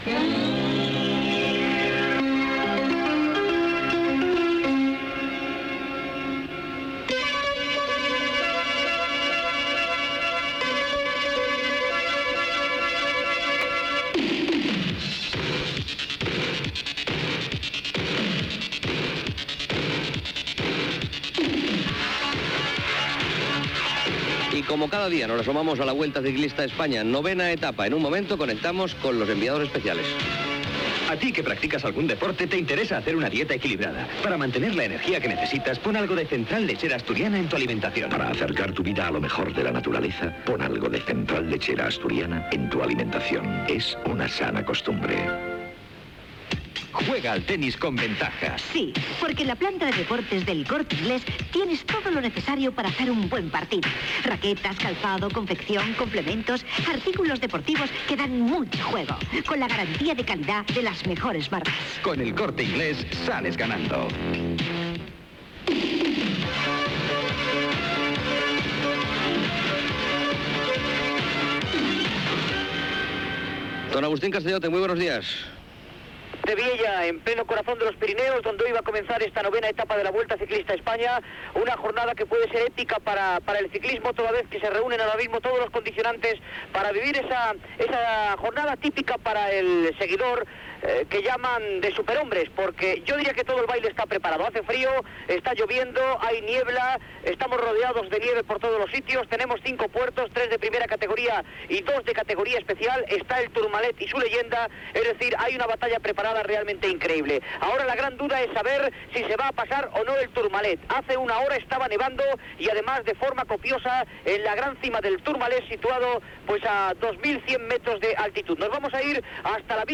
Indicatiu musical de la cadena, sintonia del programa, publicitat, connexió amb Viella des d'on surt la 9ena etapa de La Vuelta Ciclista a España.
Esportiu